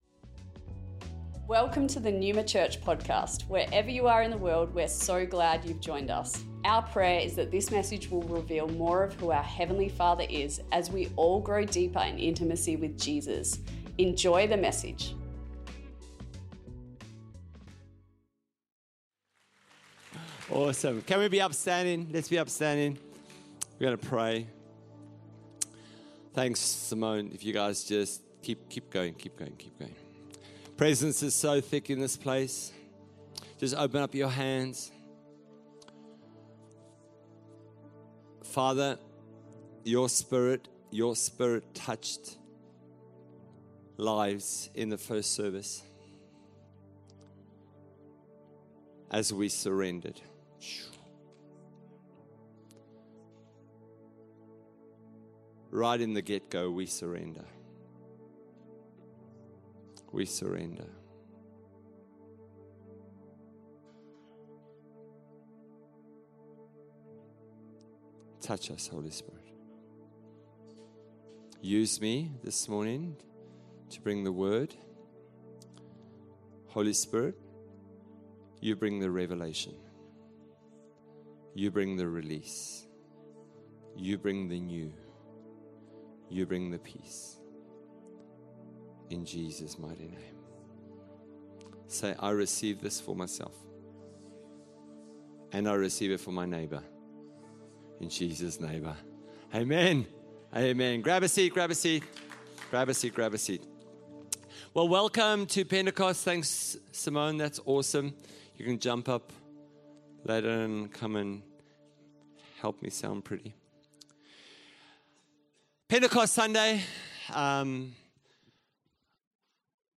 Neuma Church Melbourne East Originally recorded on Pentecost Sunday 8th of June 2025 | 11AM